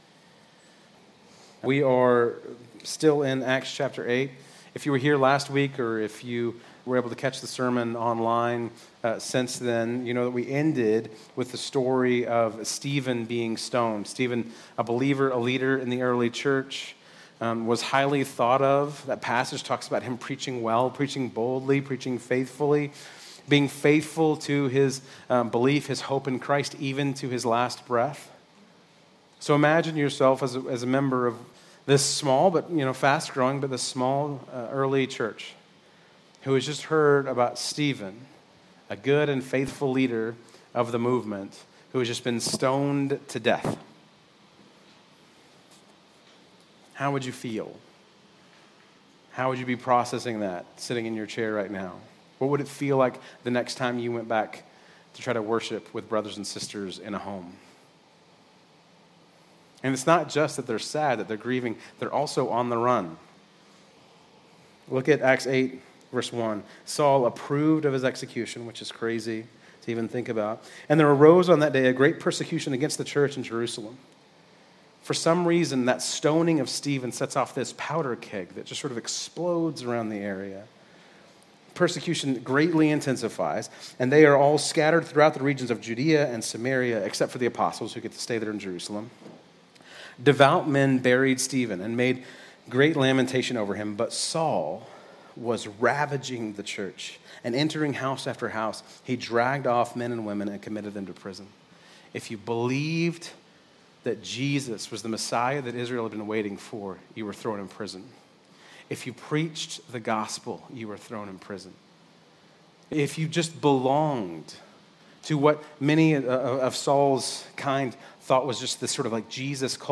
Bible Text: Acts 8:4-25 | Preacher